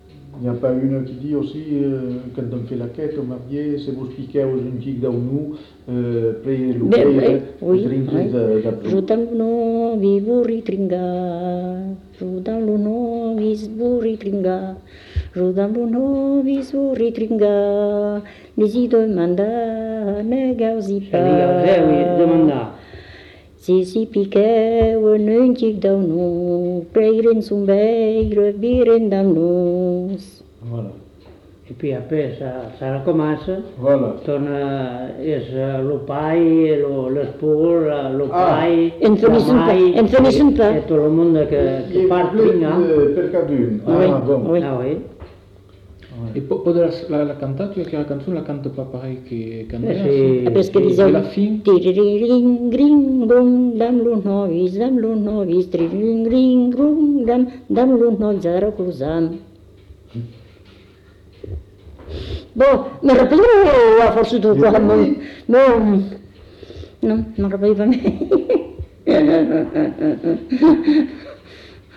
Aire culturelle : Bazadais
Lieu : Cazalis
Genre : chant
Effectif : 1
Type de voix : voix de femme
Production du son : chanté